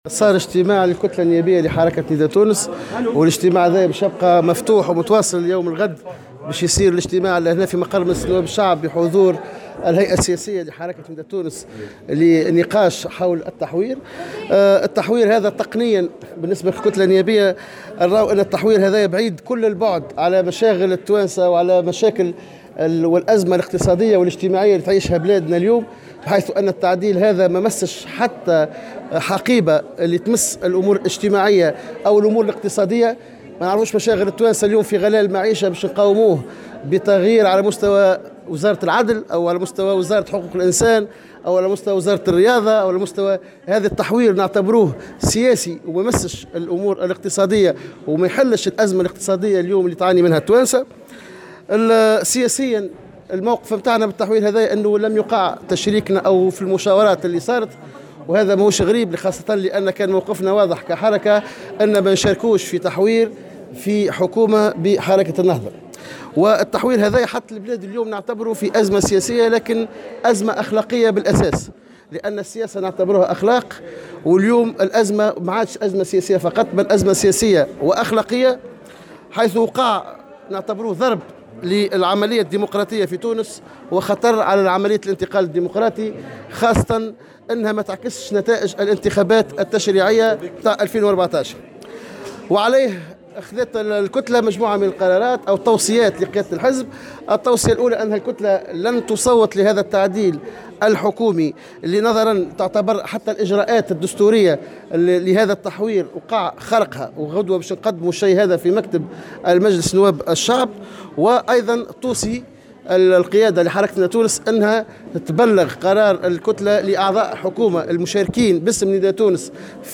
وقال رئيس كتلة نداء تونس، سفيان طوبال، في تصريح للجوهرة أف أم، إن التحوير الذي أعلن عنه يوسف الشاهد، كان بعيدا كل البعد عن المشاكل الحقيقية للتونسيين، إذ لم يمس أي حقيبة وزارية على صلة بالوضع الاقتصادي أو الاجتماعي، معتبرا أن التحوير وضع البلاد في "أزمة سياسية وأخلاقية"، مما يشكّل خطرا على الانتقال الديمقراطي على اعتبار وأن تركيبة الحكومة لم تعد تعكس نتائج انتخابات 2014.